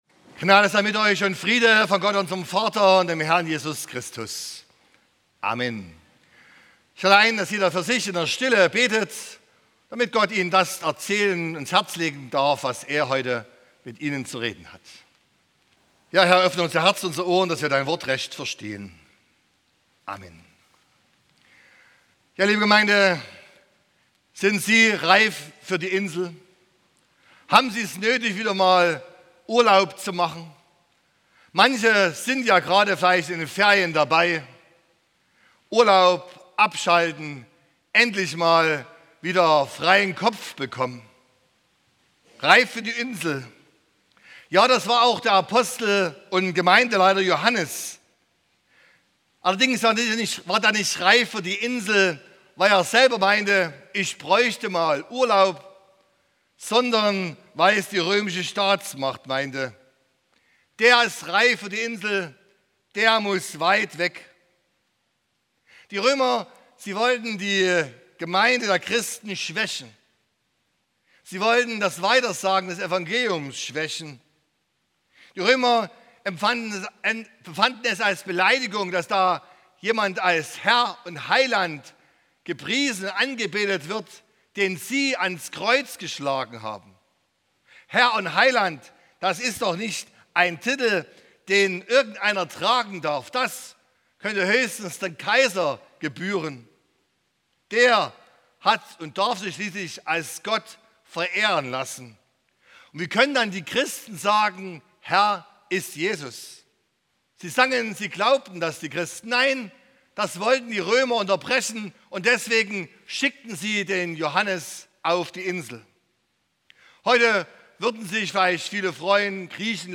07.01.2024 – Gottesdienst
Predigt und Aufzeichnungen